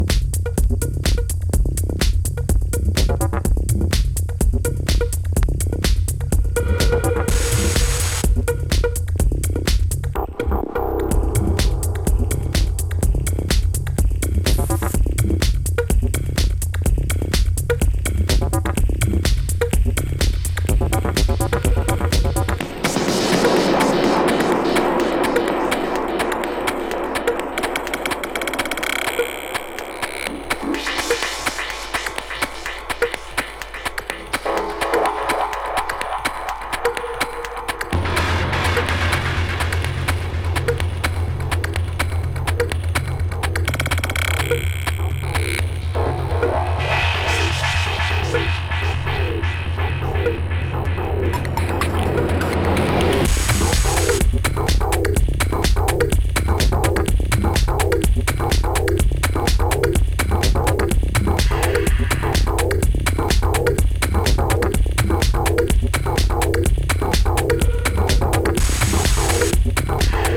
疾走感たっぷりのTech House
はAcid風味が少し入るナイストラック。